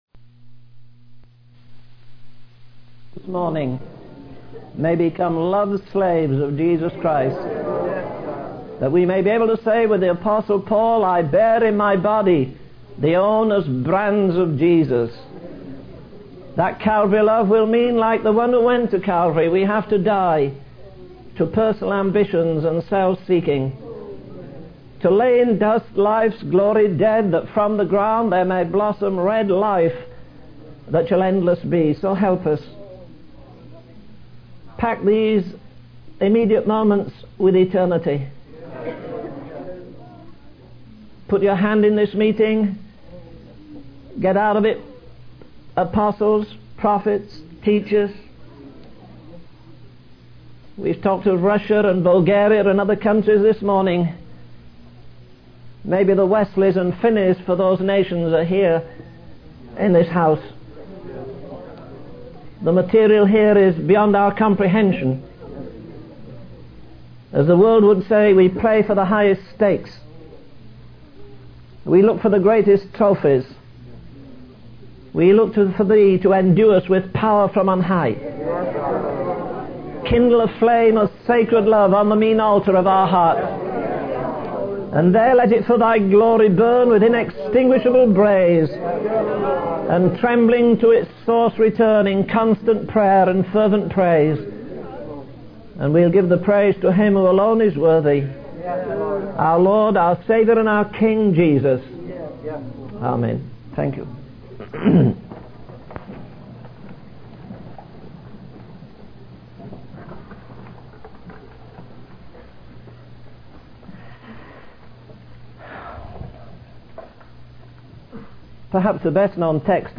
In this sermon, the speaker shares stories of revival and the power of prayer.